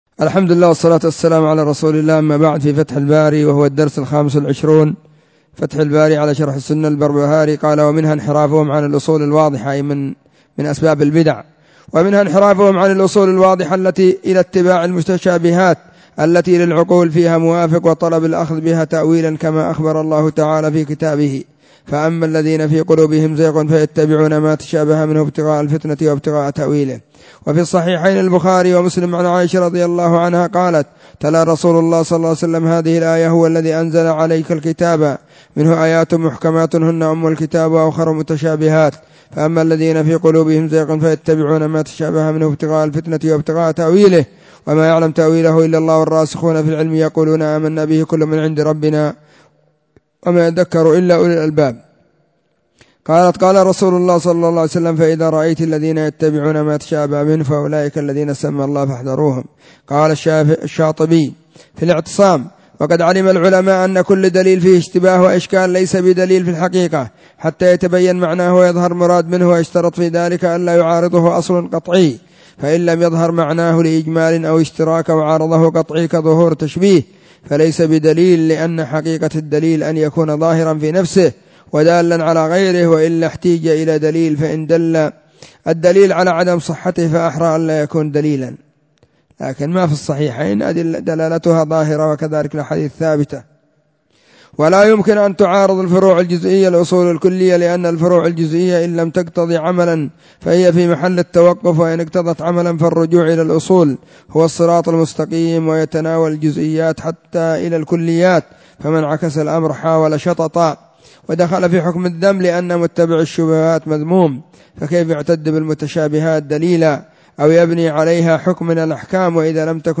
📢 مسجد الصحابة – بالغيضة – المهرة، اليمن حرسها الله.
📗 الدرس 25